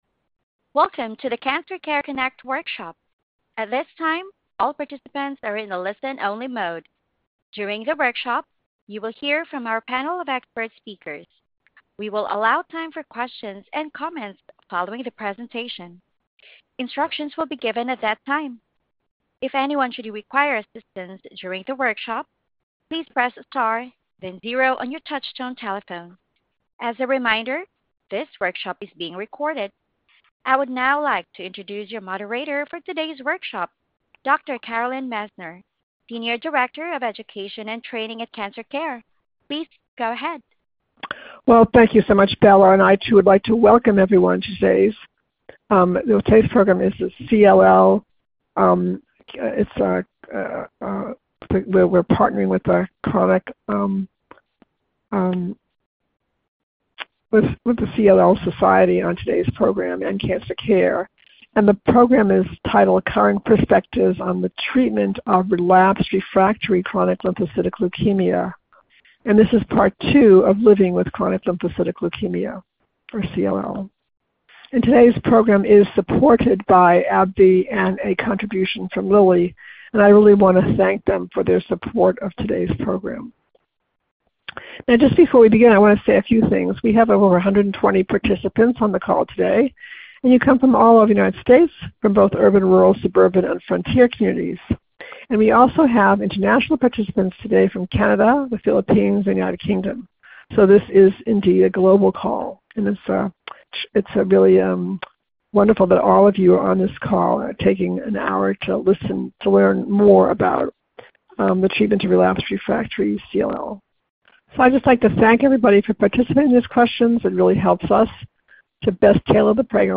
Questions for Our Panel of Expert